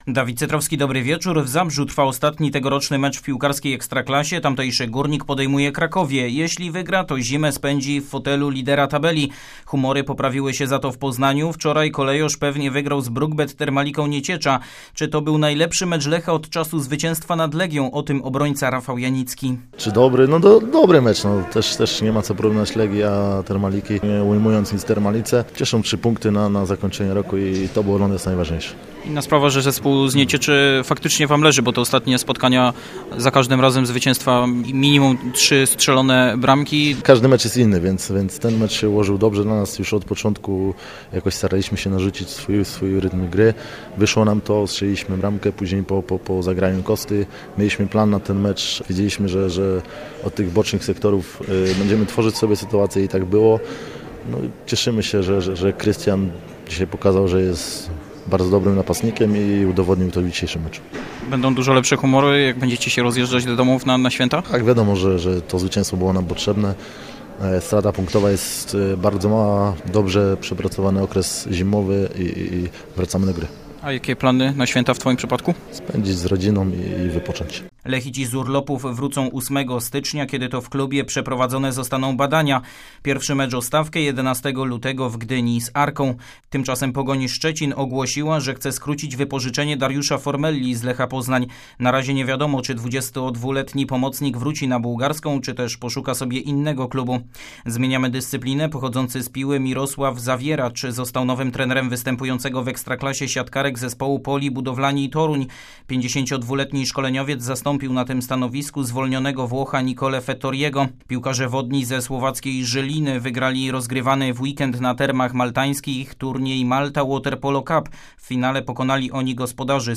18.12 serwis sportowy godz. 19:05